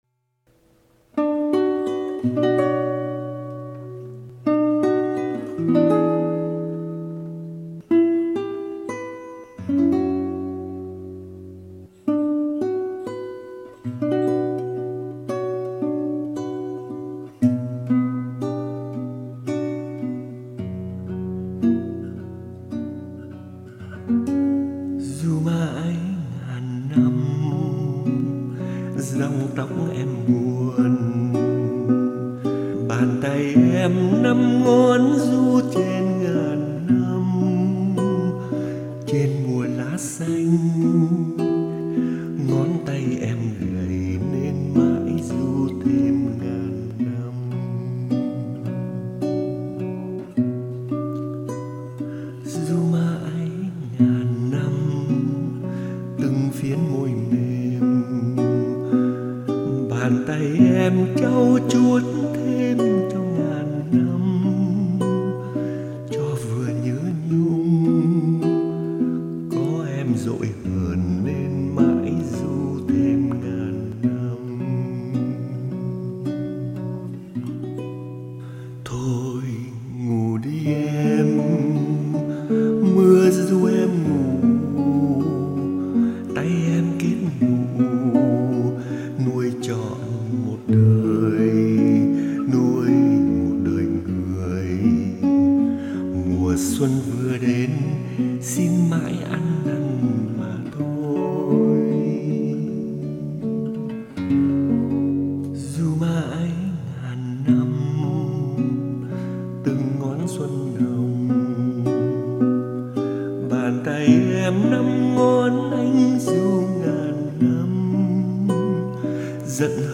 đàn và hát.